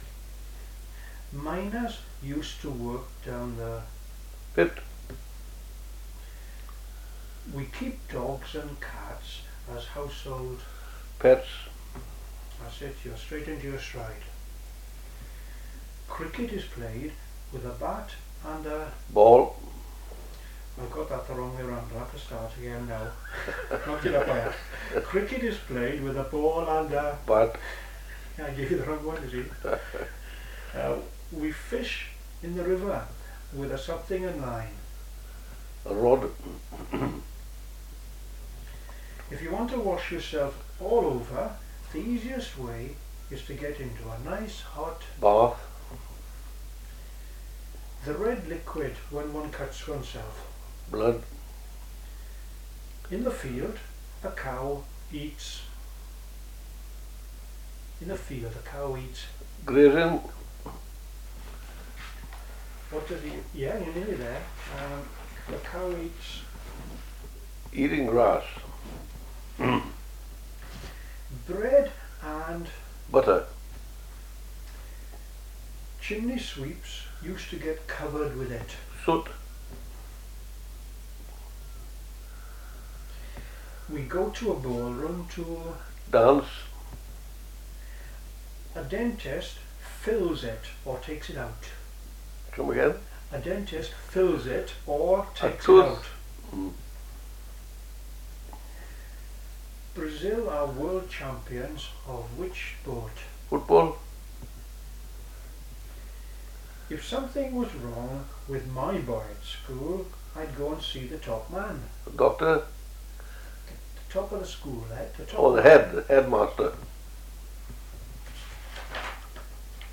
the-phonology-of-rhondda-valleys-english.pdf
Treherbert3Questionnaire.mp3